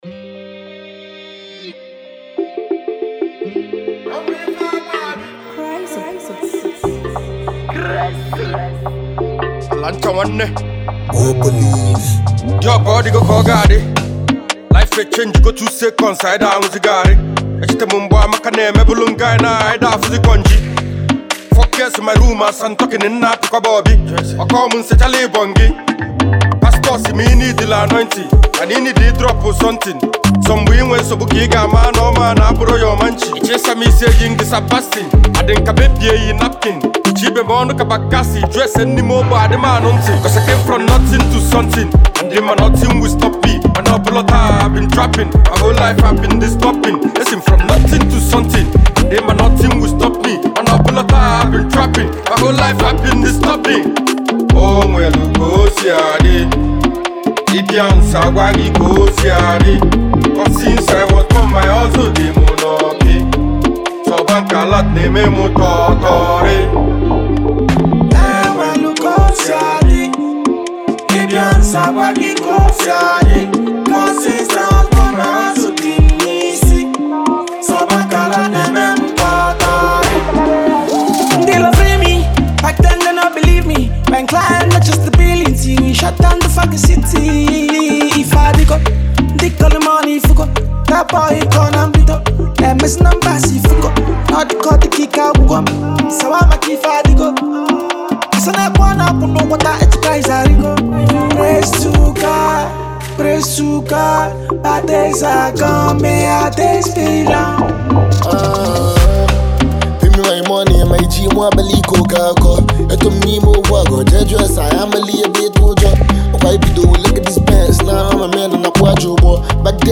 Fast Rising super talented Eastern rapper and singer